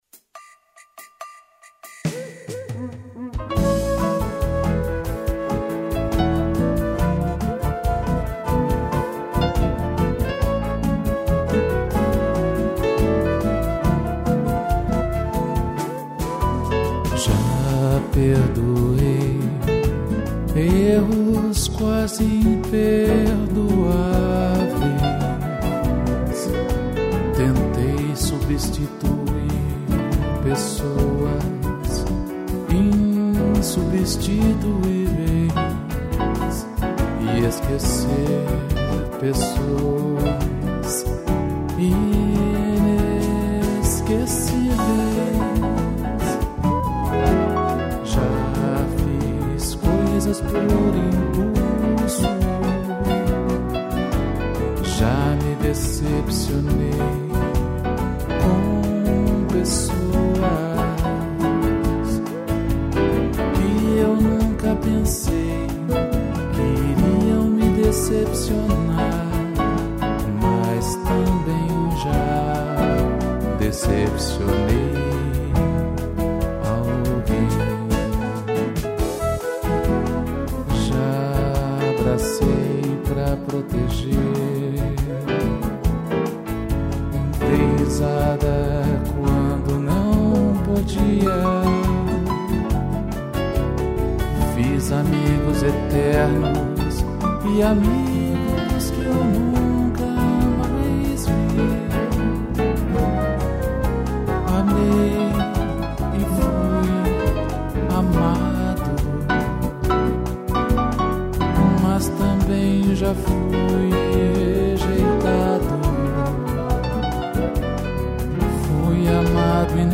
piano e cuíca